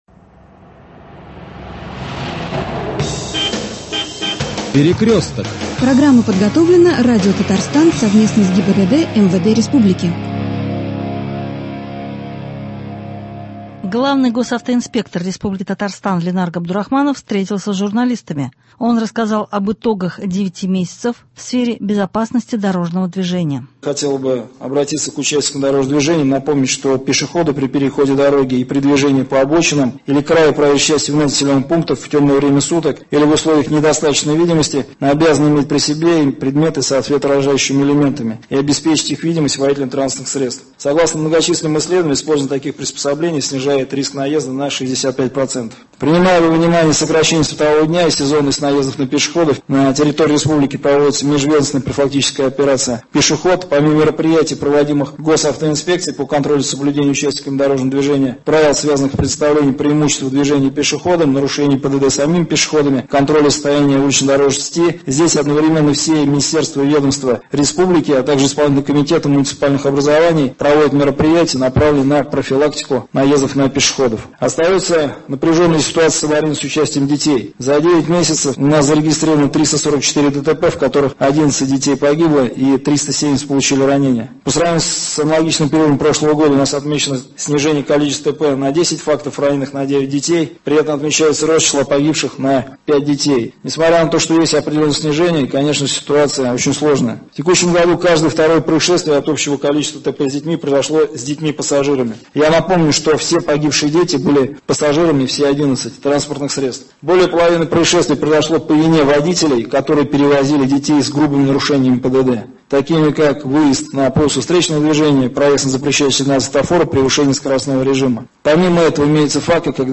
Пресс — конференция наальника УГИБДД МВД по РТ Ленара Габдурахманова: итоги 9 месяцев, световозвращающие элементы, дети — участники ДТП, переоформление ТС.